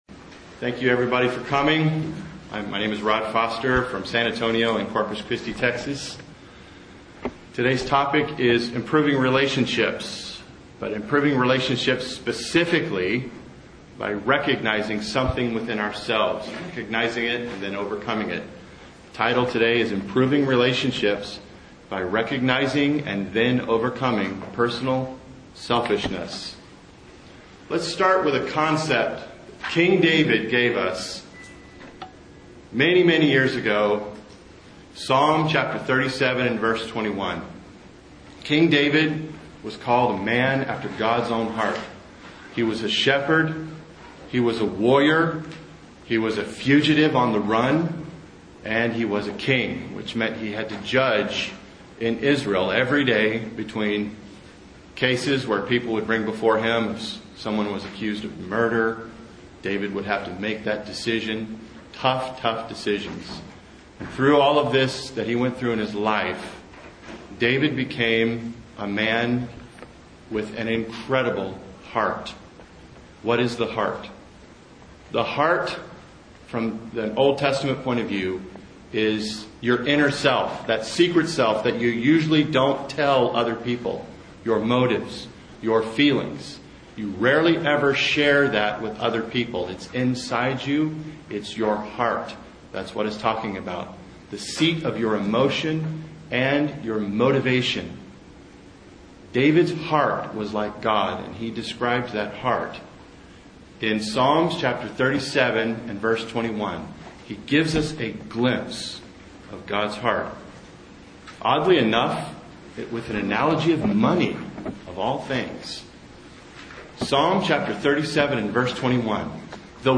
This sermon was given at the Galveston, Texas 2011 Feast site.